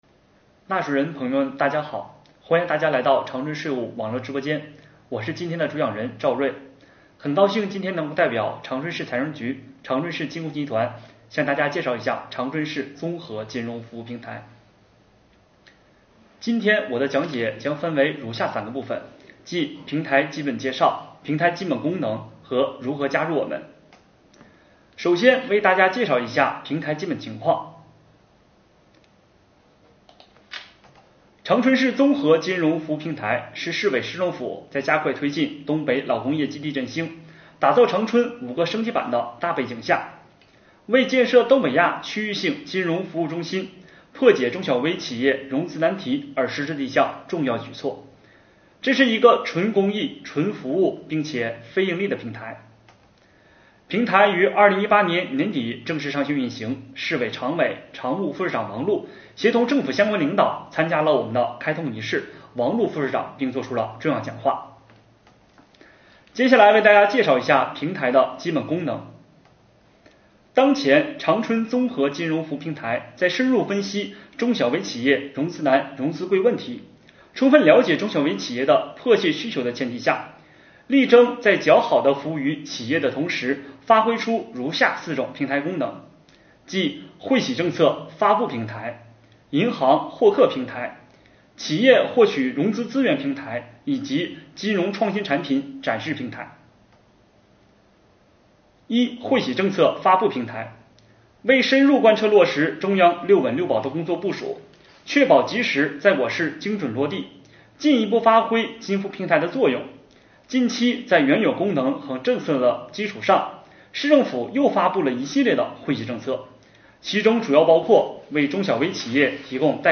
2020年第56期直播回放：长春市综合金融服务平台介绍